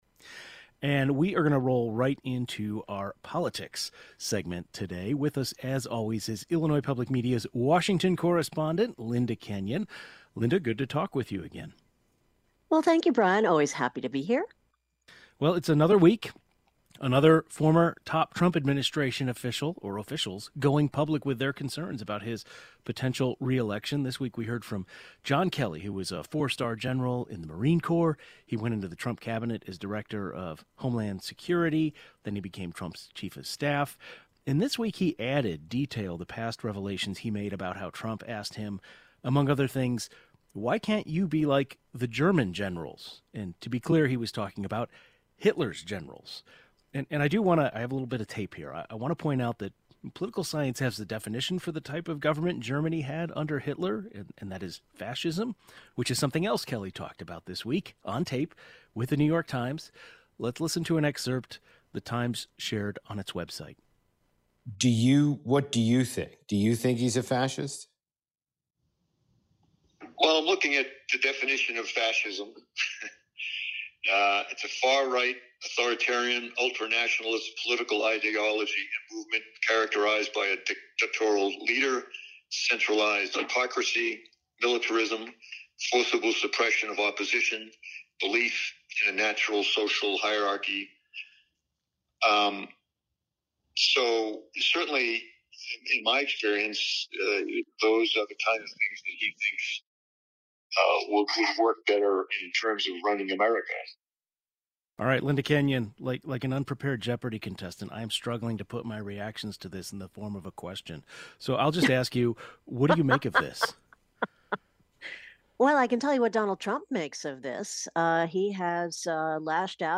Our Friday politics roundtable: